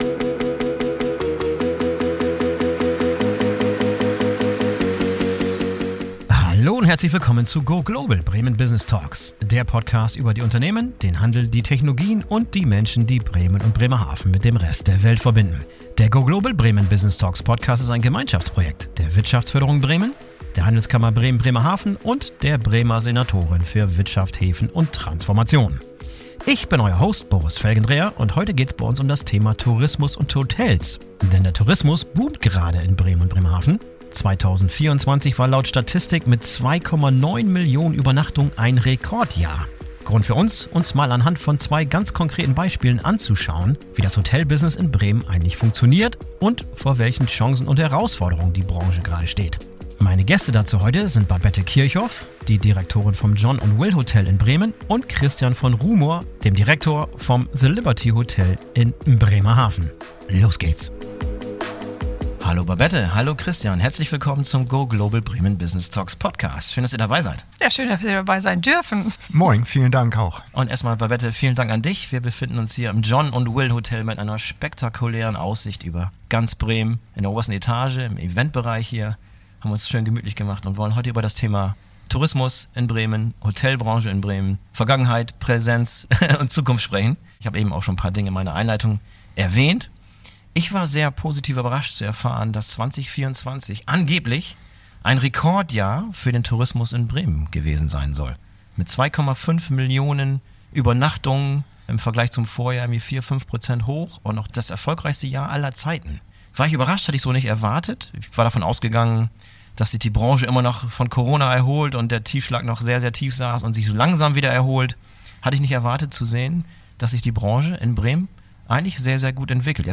Der Tourismus in Bremen und Bremerhaven boomt: Wie funktioniert das Business von zwei Vorzeige-Boutique-Hotels? ~ Go Global! Bremen Business Talks Podcast